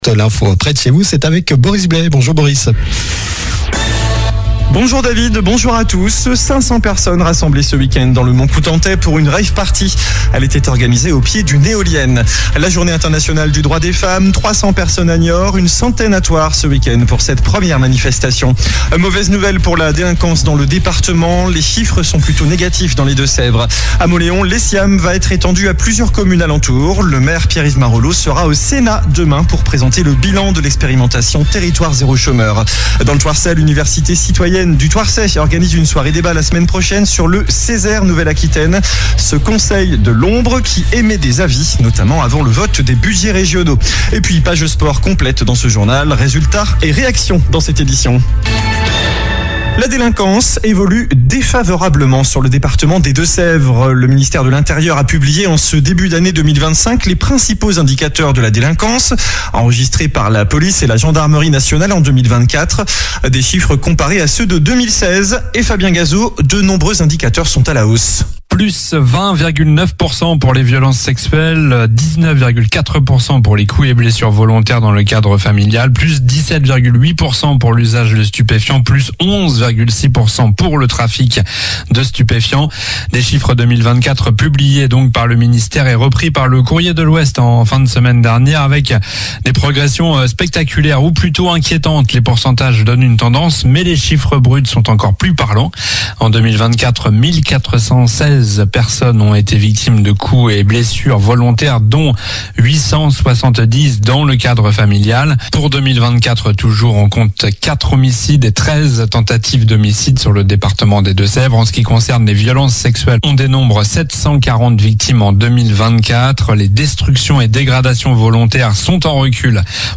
Journal du lundi 10 mars (midi)